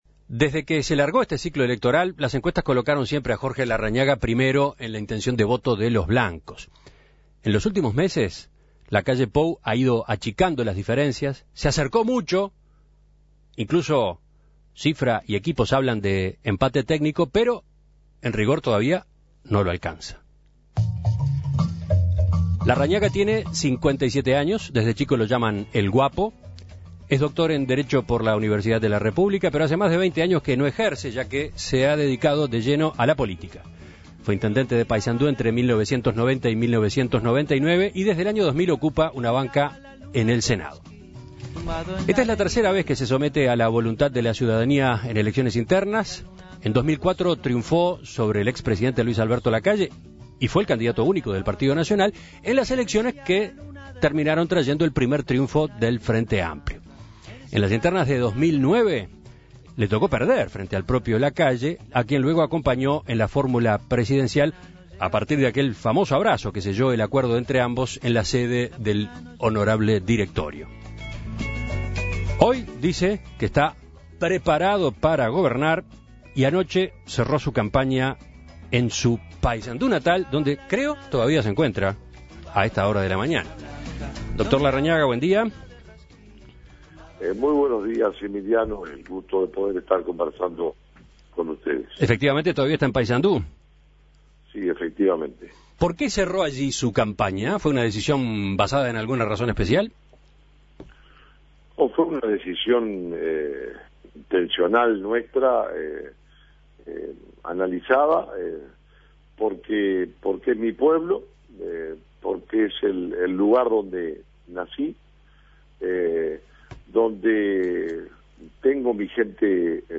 En el día previo al comienzo de la veda electoral, En Perspectiva conversó con los precandidatos nacionalistas.